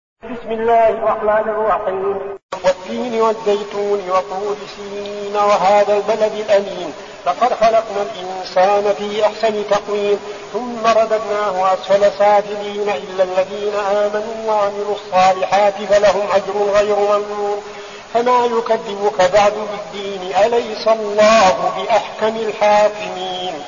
المكان: المسجد النبوي الشيخ: فضيلة الشيخ عبدالعزيز بن صالح فضيلة الشيخ عبدالعزيز بن صالح التين